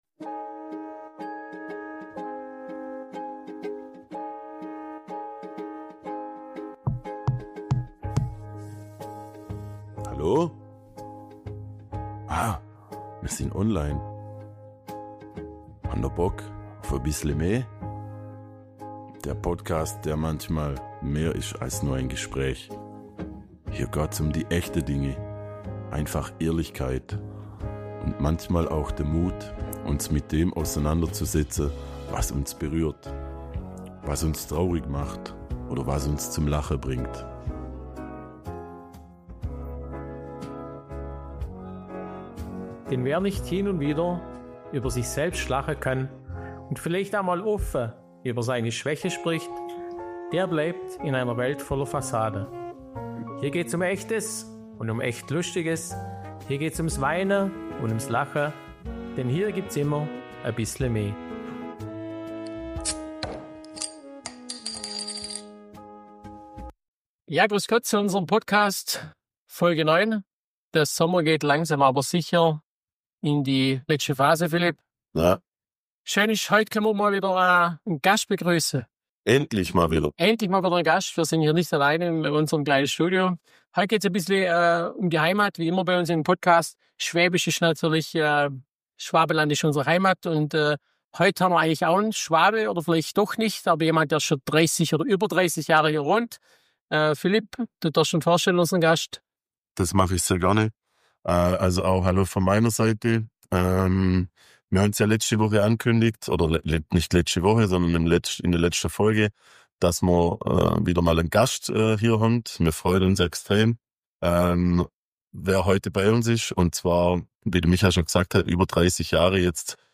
Ein humorvoller, sympathischer Blick über Grenzen hinweg – mit viel Augenzwinkern und noch mehr Dialekt.